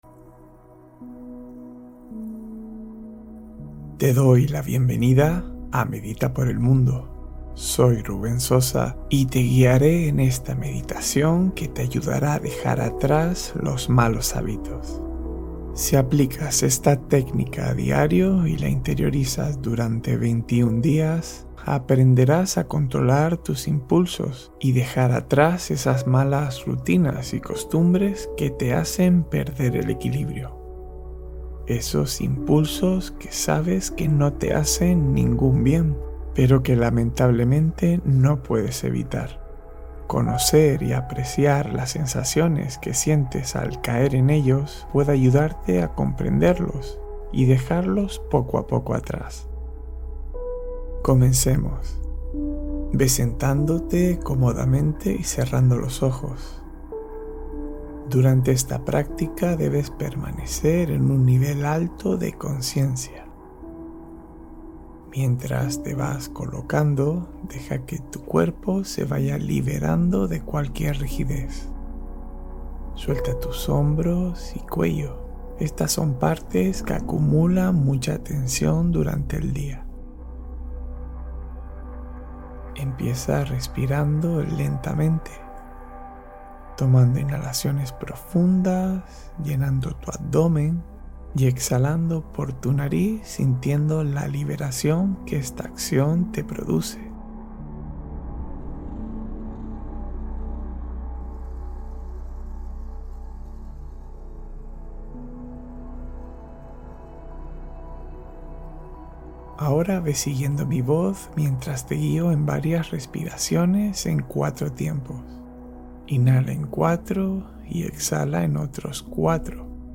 Curación Interior en 10 Minutos: Meditación de Reequilibrio Profundo